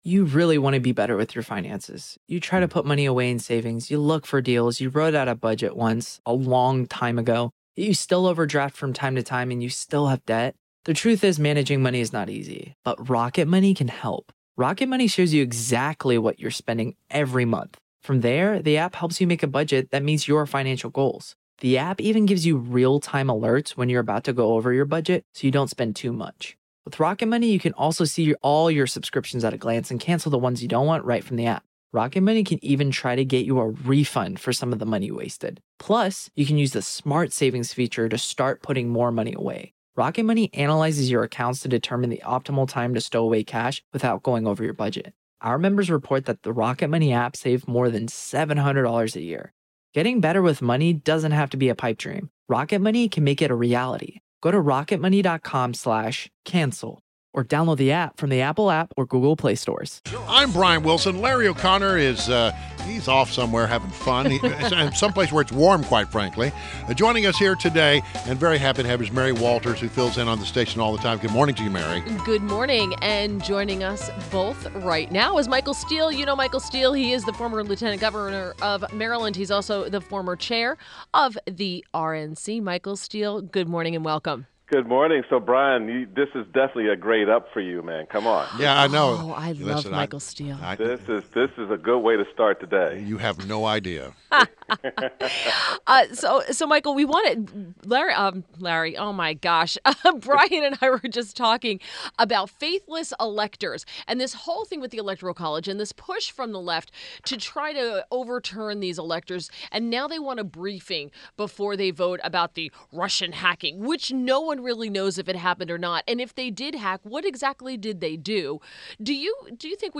INTERVIEW — MICHAEL STEELE – Former RNC Chairman and Former Lt. Gov. Maryland